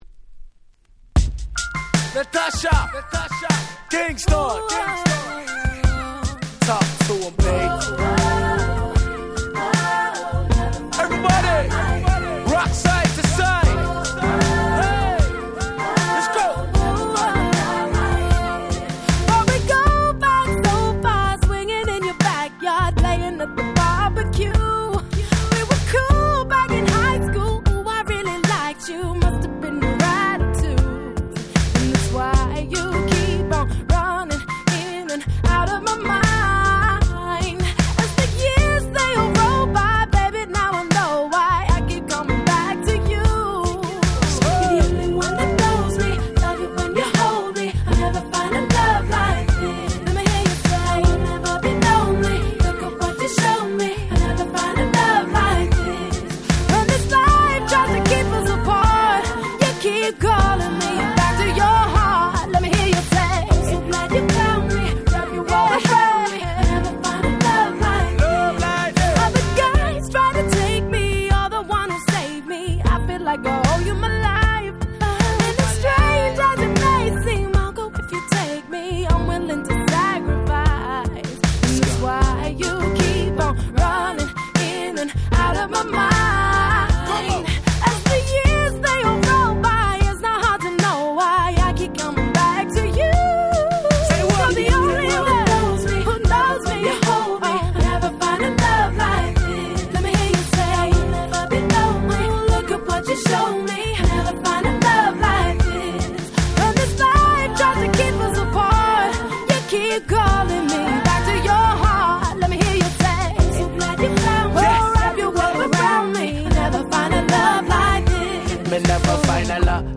07' Super Hit R&B !!
キラキラ系